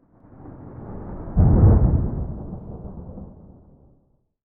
pre_storm_5.ogg